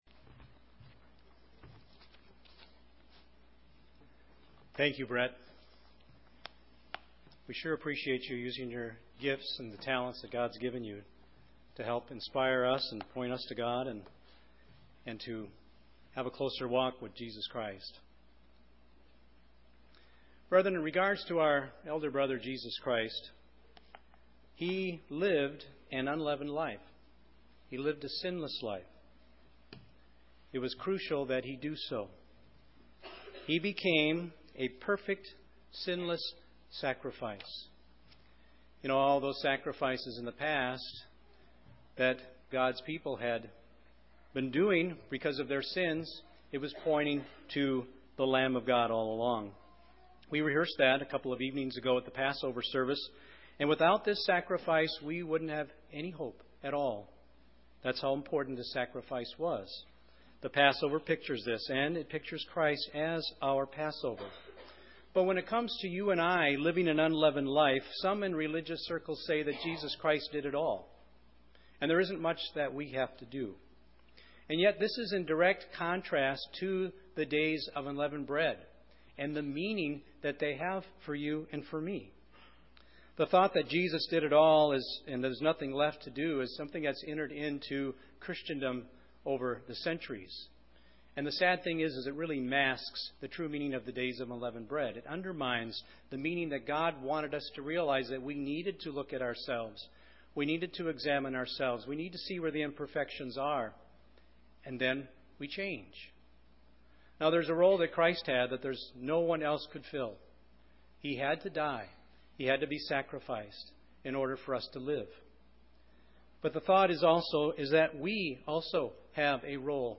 Both Jesus and we have a role to play. This message was given on the First Day of Unleavened Bread.